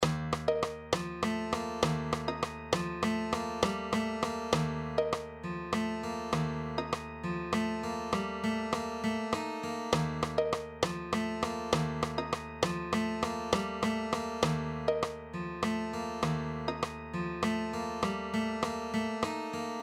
Example 1: 15/8 and 9/4 count: